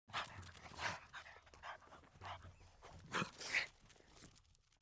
جلوه های صوتی
دانلود صدای سگ 2 از ساعد نیوز با لینک مستقیم و کیفیت بالا
برچسب: دانلود آهنگ های افکت صوتی انسان و موجودات زنده دانلود آلبوم صدای انواع سگ از افکت صوتی انسان و موجودات زنده